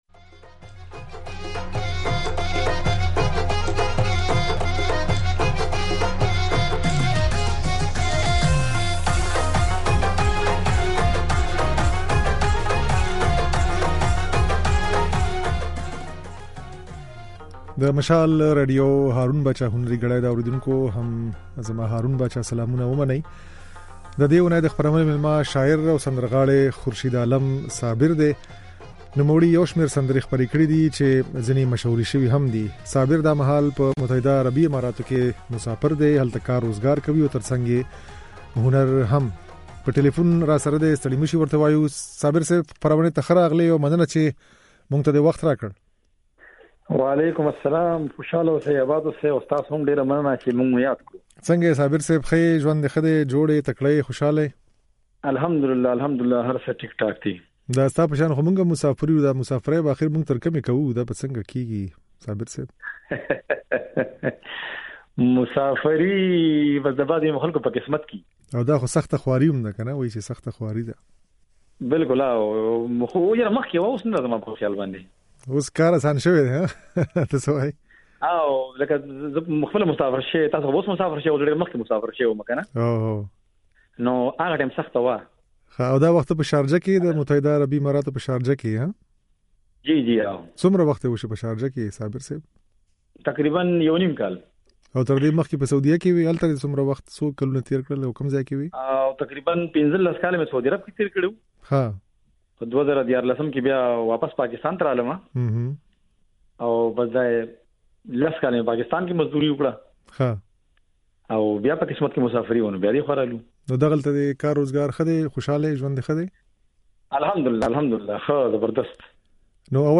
ځينې سندرې يې په خپرونه کې اورېدای شئ